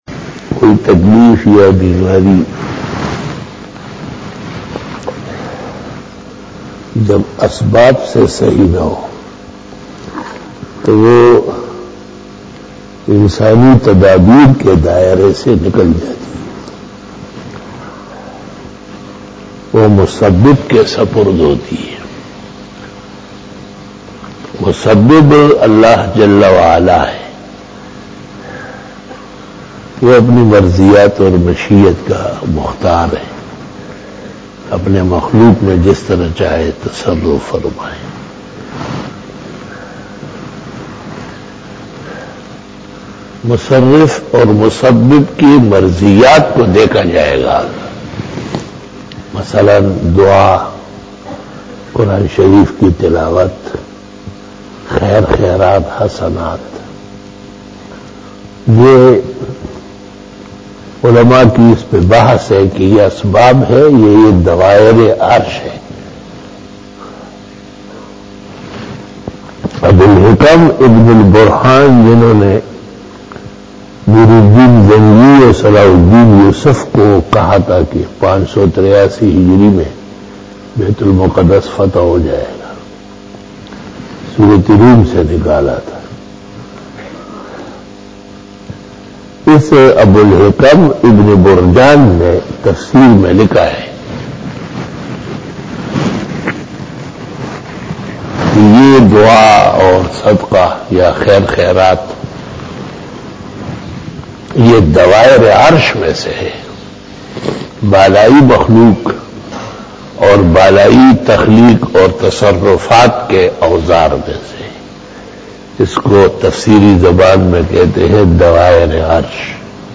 After Fajar Byan
بیان بعد نماز فجر